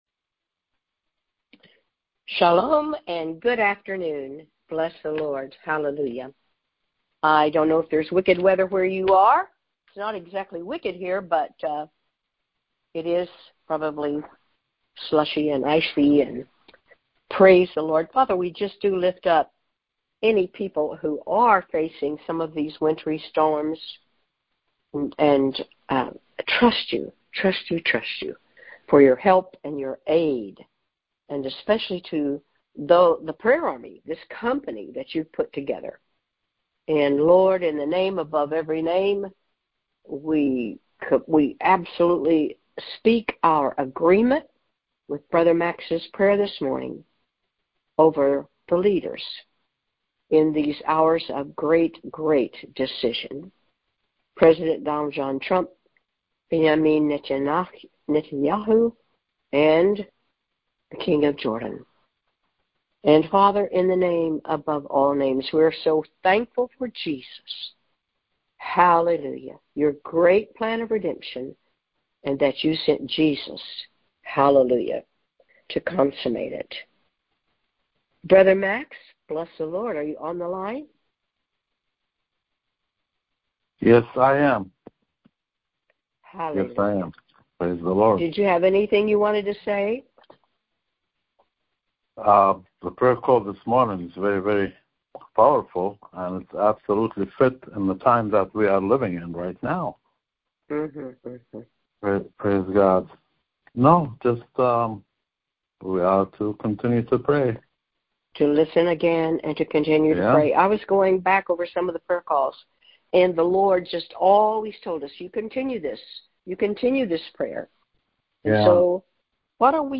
Wednesday Noon Prayer
The audio was recorded via our BBM Phone Cast system.